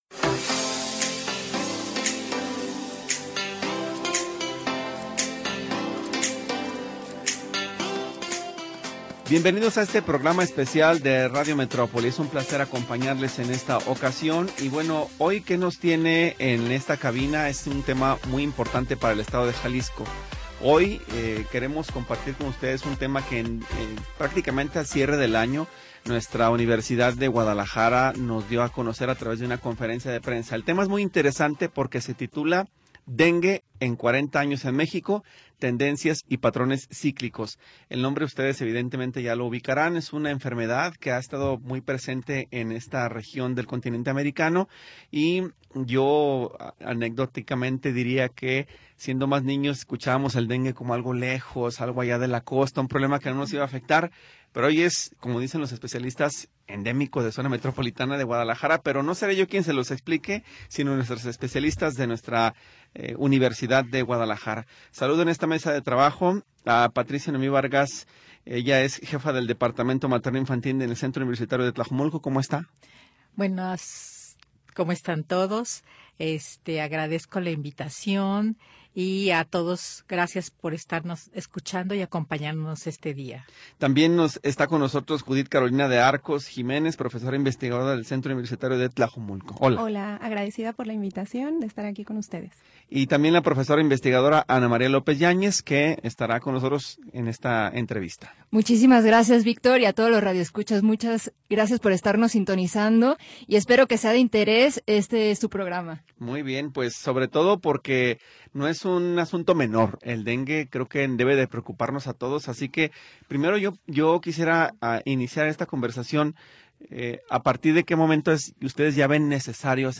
Primera hora del programa transmitido el 2 de Febrero de 2026.